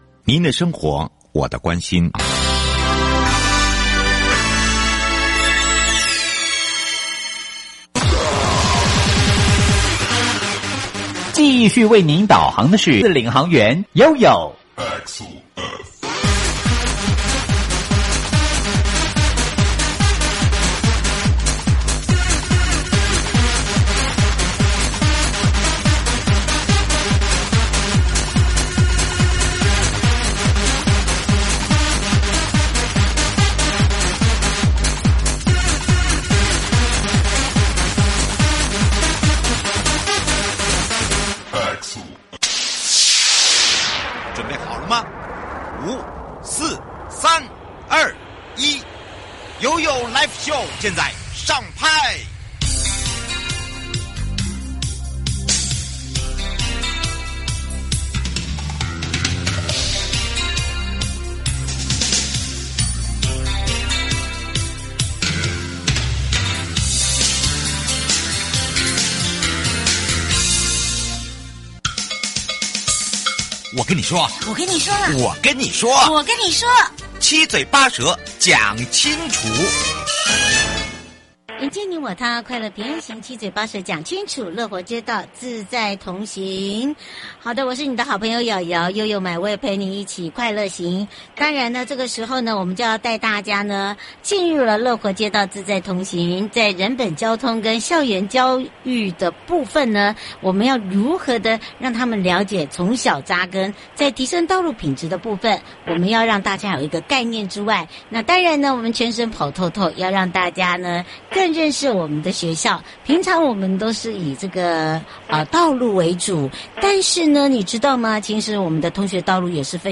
受訪者： 營建你我他 快樂平安行-主題「樂活街道自在同行」人本交通及校園教育宣導–(第二集) 南投縣魚池國小對